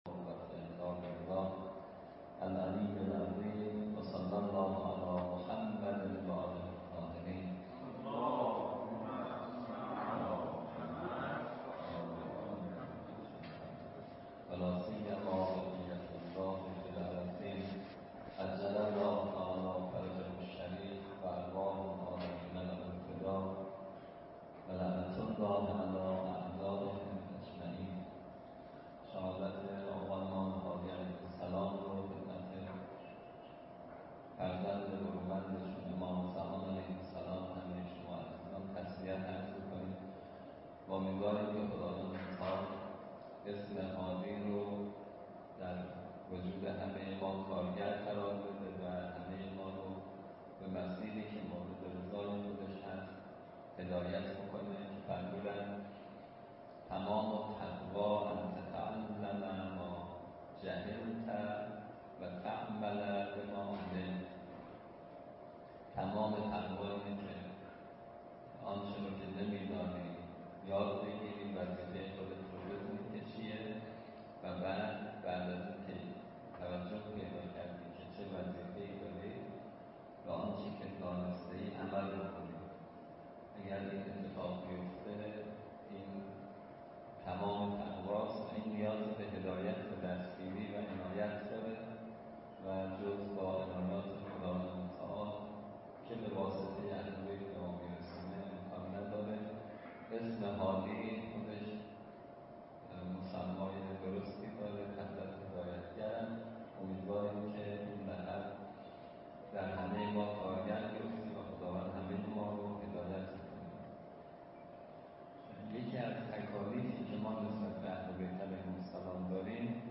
مراسم توسل به مناسبت شهادت حضرت هادی علیه‌السلام
با مرثیه سرایی